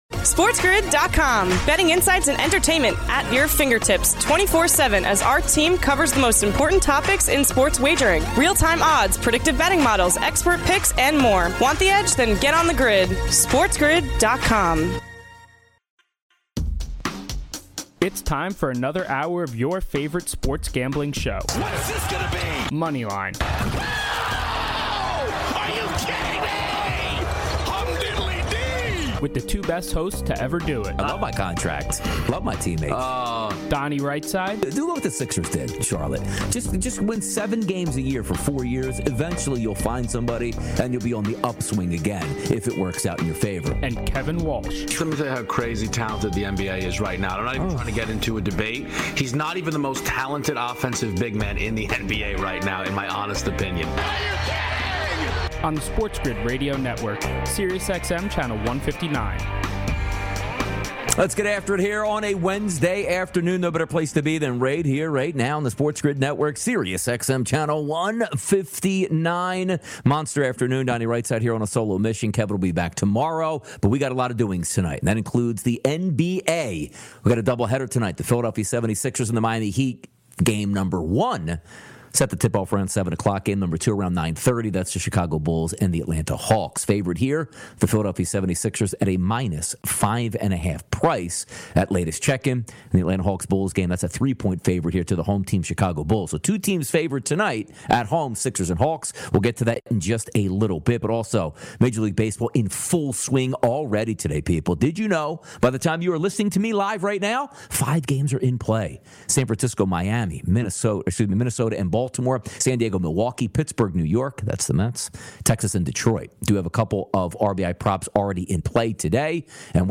Then, he breaks down the two play-in games tonight. He gives you all his best bets from those two games, takes your calls, and more.